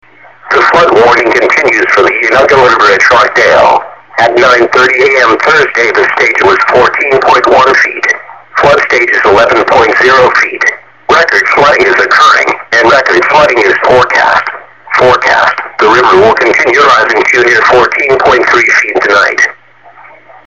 Flood Warning - Unadilla River at Rockdale